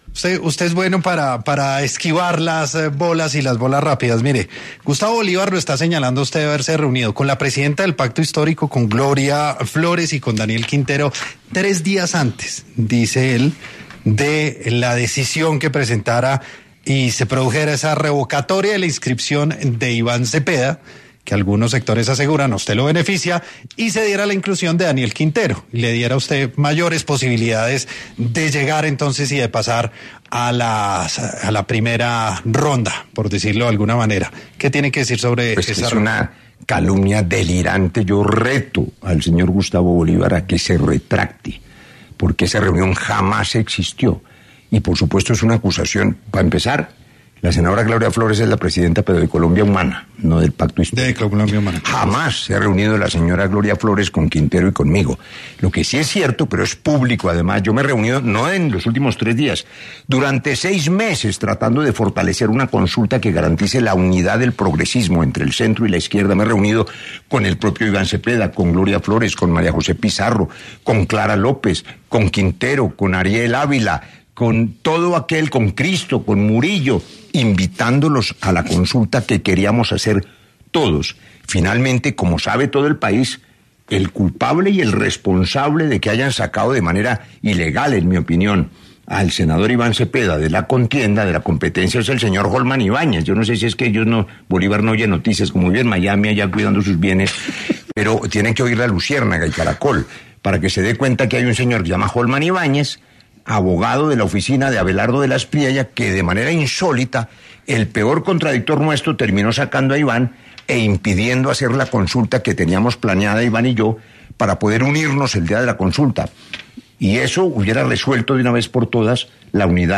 Este lunes, 9 de febrero, en los micrófonos de La Luciérnaga, el precandidato presidencial Roy Barreras habló en la sección ‘Sin Anestesia’ y desmintió los señalamientos del exsenador Gustavo Bolívar, quien aseguró que la senadora Gloria Flórez, presidenta de la Colombia Humana, se reunió con el mismo Barreras y Daniel Quintero días antes de que el CNE revocara la inscripción de Iván Cepeda en la consulta del ‘Frente por la Vida’.